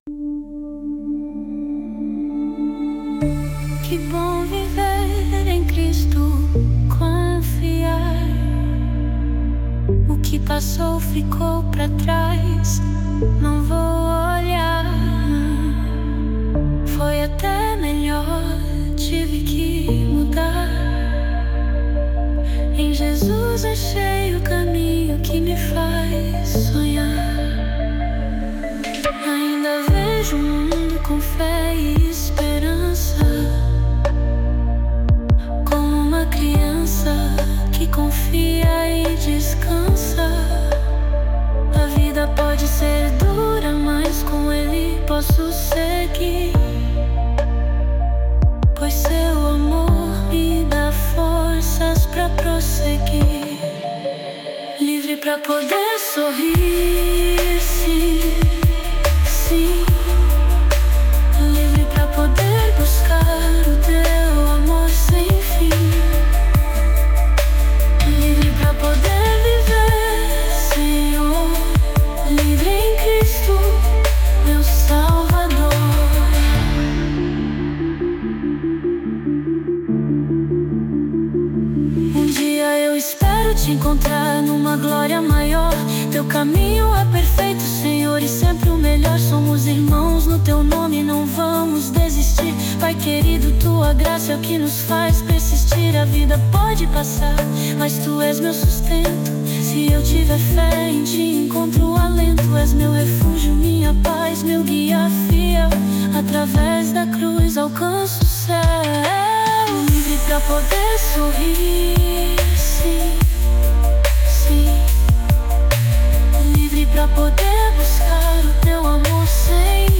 Baixar Música Grátis: Louvores com Inteligência Artificial!